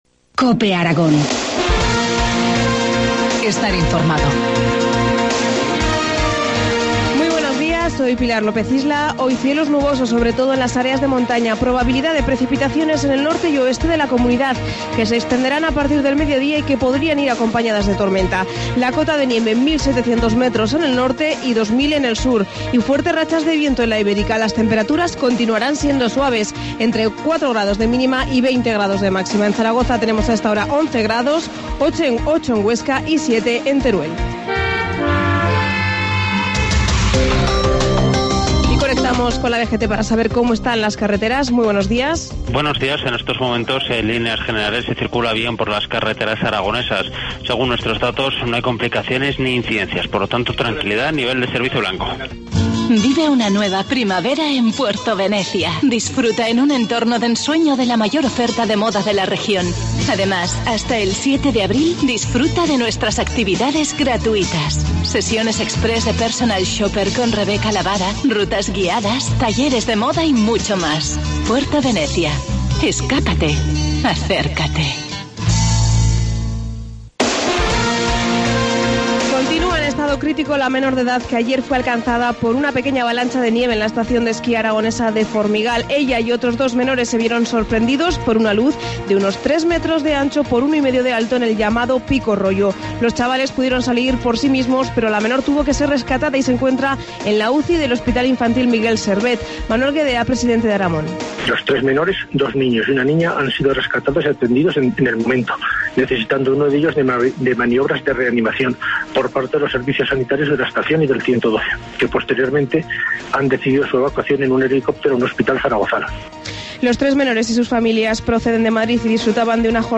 Informativo matinal, martes 26 de marzo, 7.25 horas